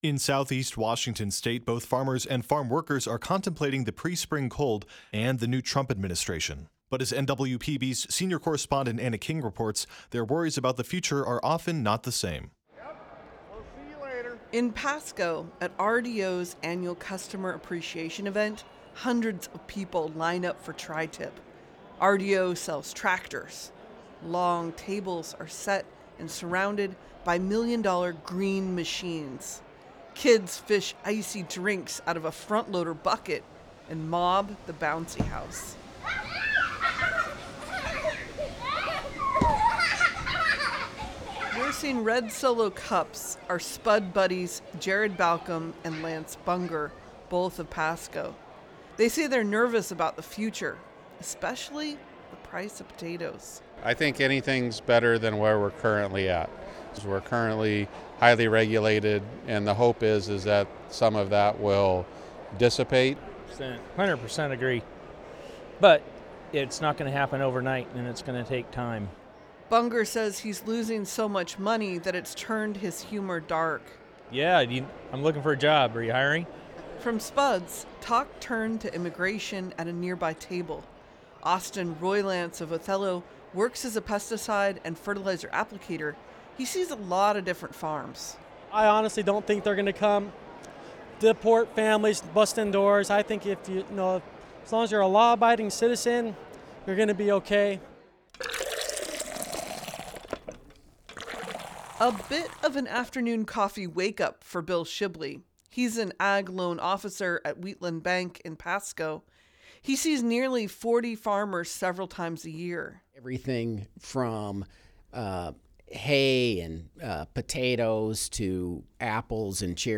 The aluminum ladders rattled and clanged as workers lopped off high branches.